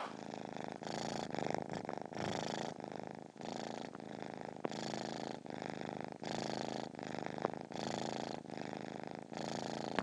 Purring kitty